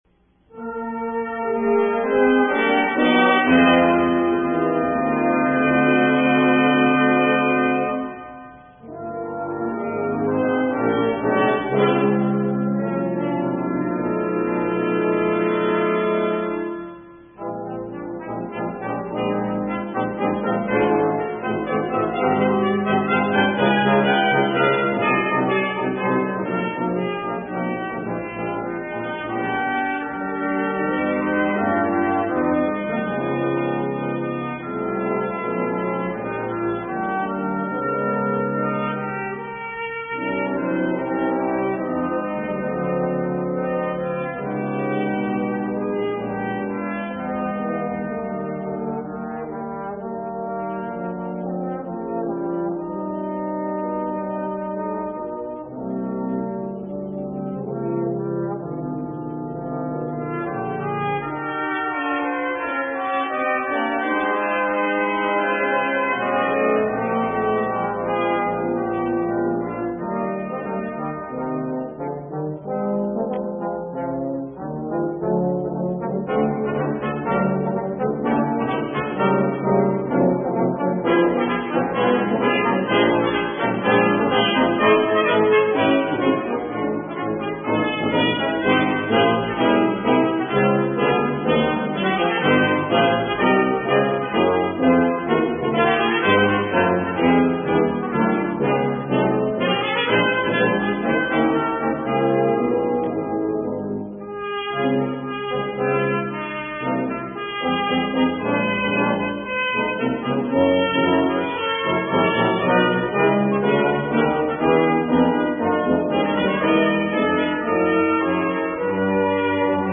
Eb, Ab, F
Brass sextet
Song (ternary)